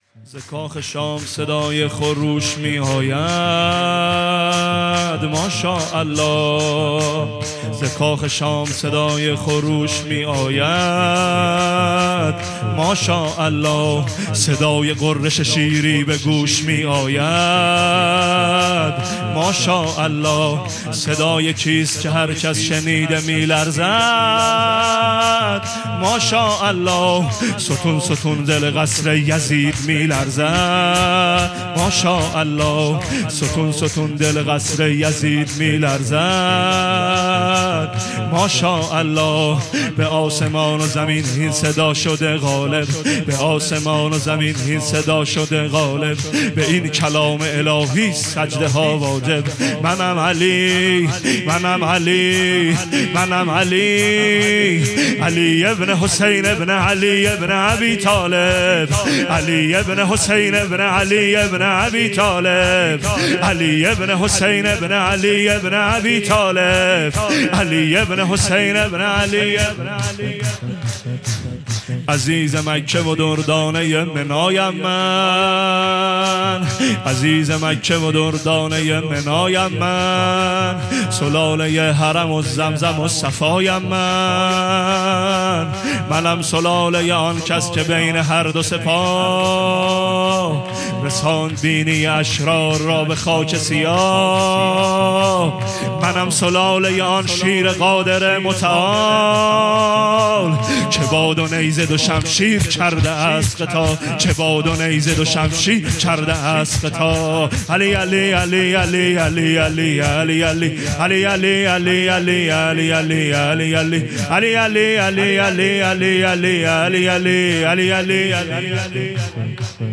هیئت محبان الحسین علیه السلام مسگرآباد